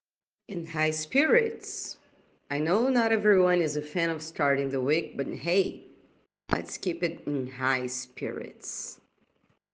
Check pronunciation: